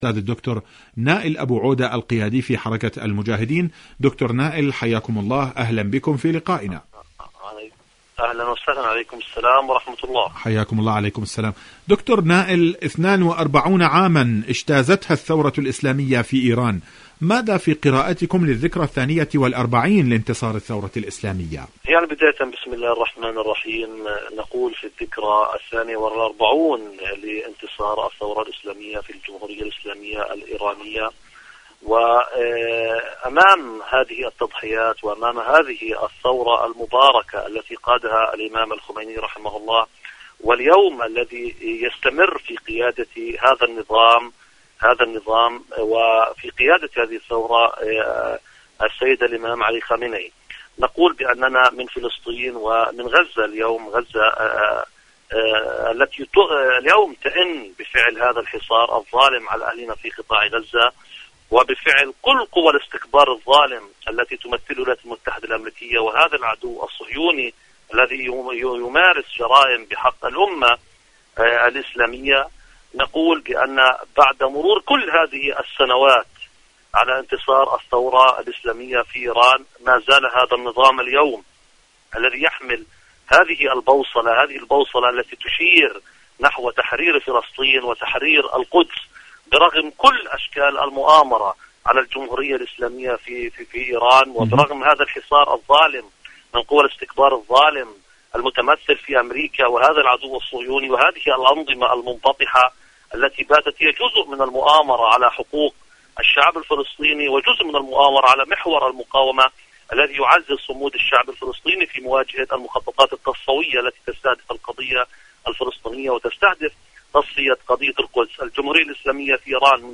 إذاعة طهران-فلسطين اليوم: مقابلة إذاعية